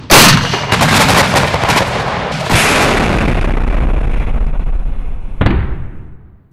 explosion 3